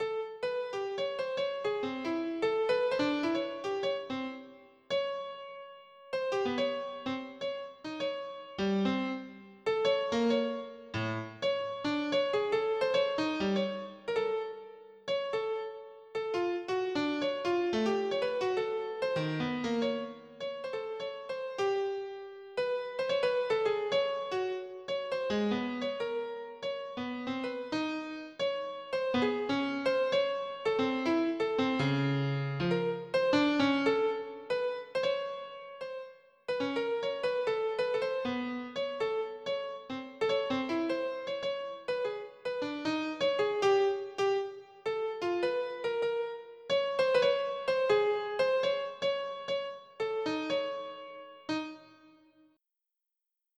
• Качество: 320, Stereo
без слов
пианино
море
Ещё и ветер неслабый!